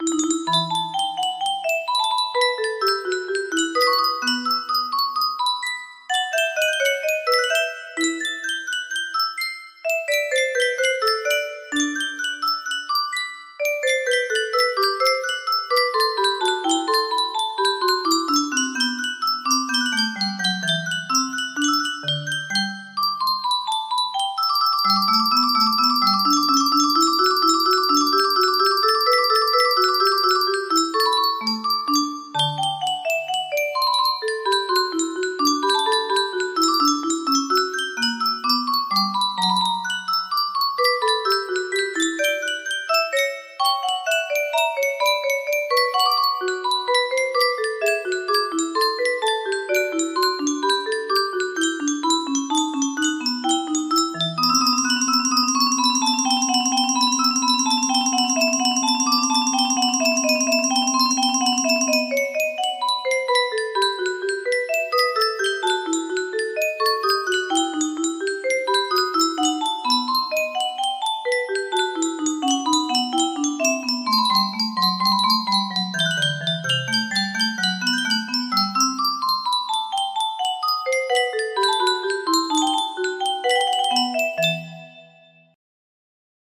Bach Invention No. 7 in E Minor BWV 778 music box melody
Full range 60